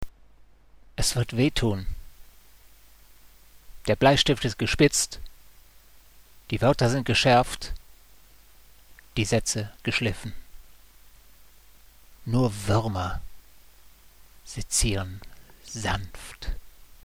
(Inwendig vorgetragen:)